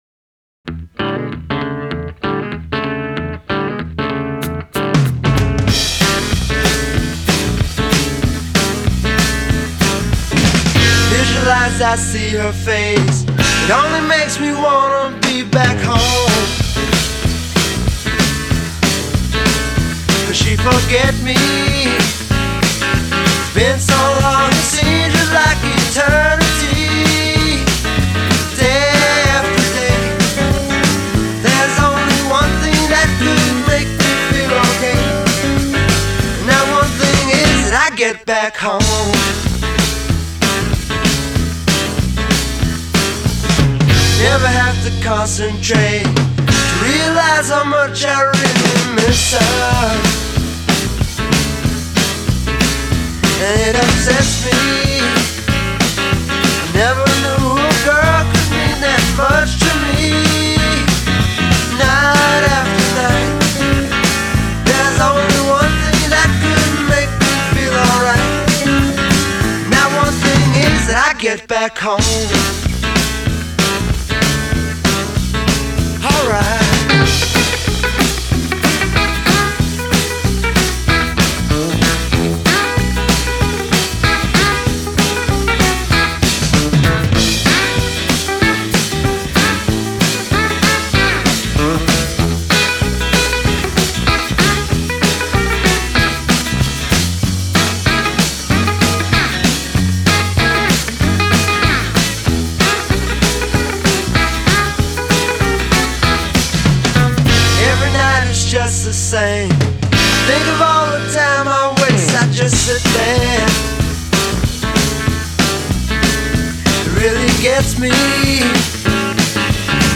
Beatlesque